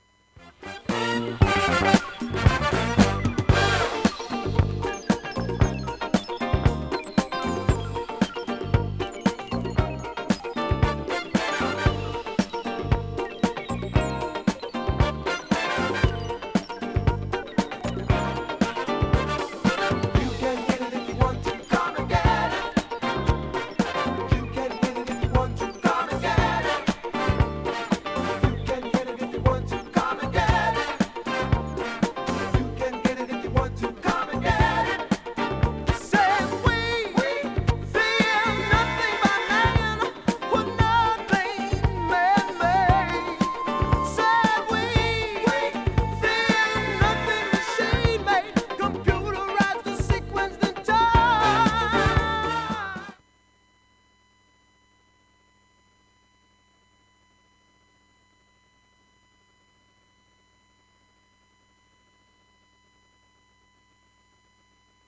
現物の試聴（上記録音時間1分）できます。音質目安にどうぞ
程度のわずかなプツが聴こえます。
残した唯一のレアモダンソウルLP
グレイトメロウグルーヴA2